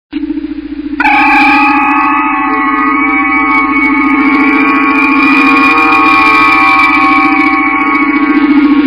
Tags: Star Trek tv series Star Trek transporter sound Star Trek door sound doors